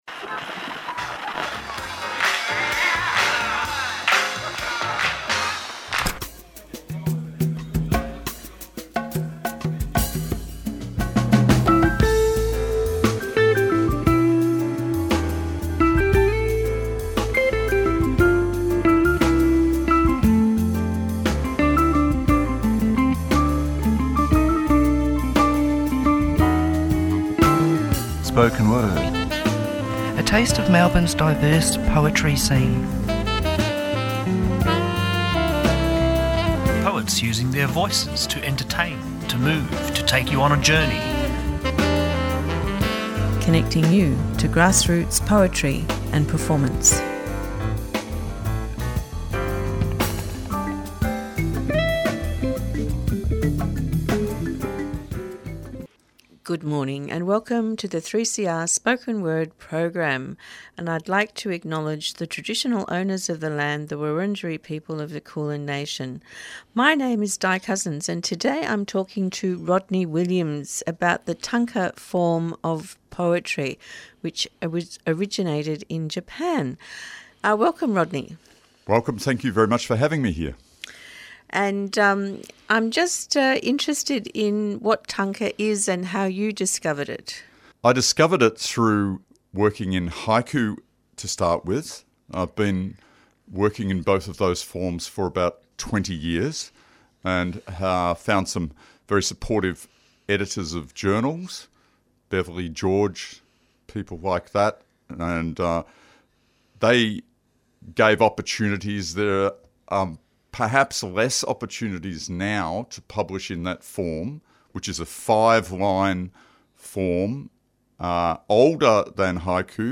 Spoken Word
Guests are contemporary poets who read and discuss their works.